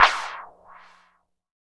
12 CLAP   -R.wav